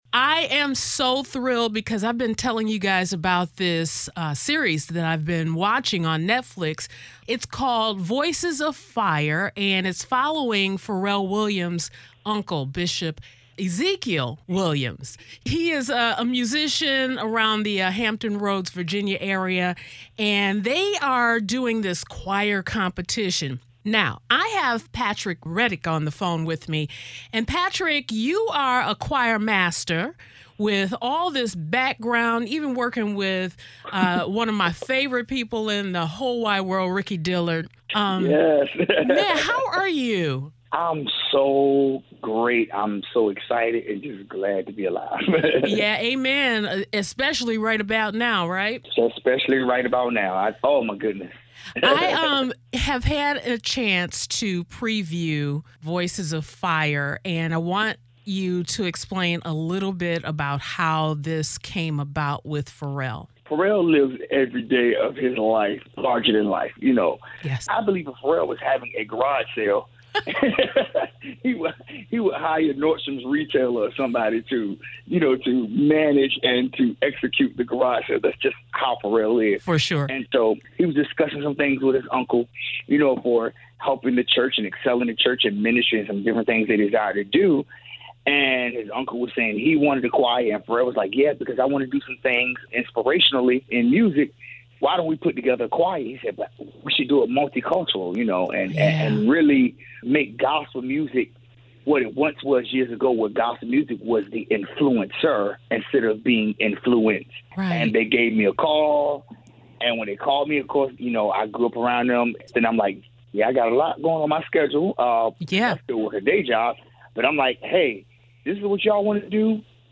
I Dare You Not To Shout While Watching “Voices of Fire” [[Exclusive Interview]]